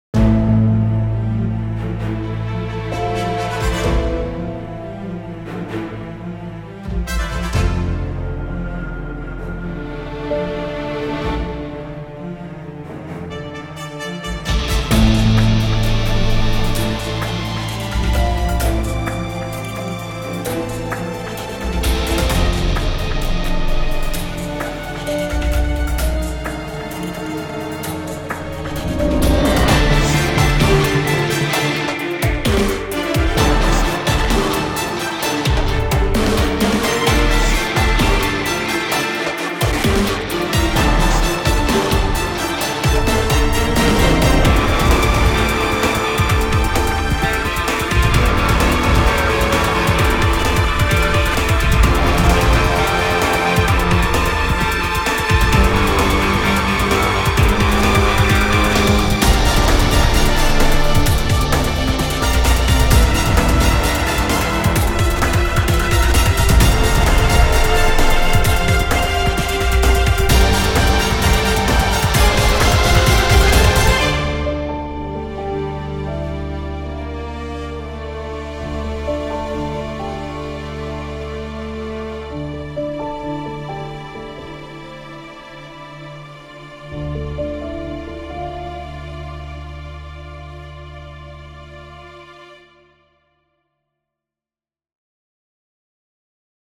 Sci Fi / Action